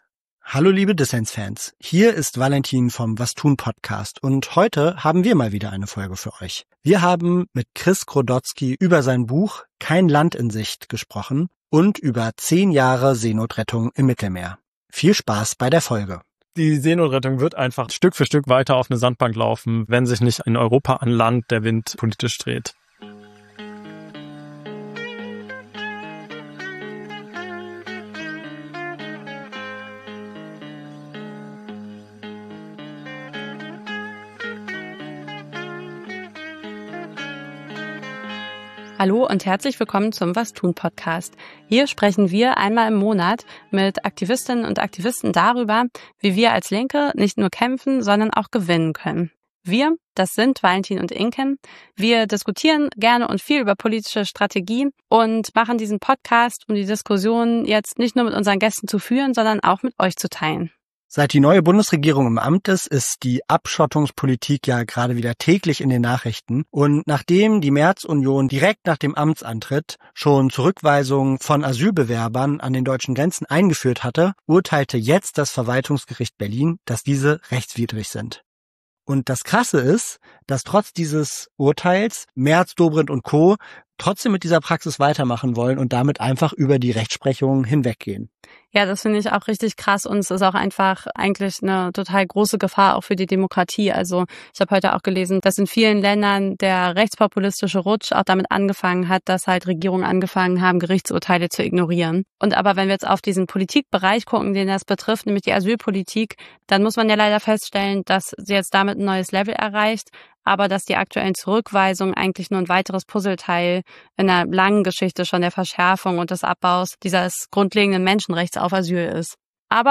Interview Damals wie heute: Arbeitskämpfe sind ein zentraler Hebel, um Zeit gerechter zu verteilen.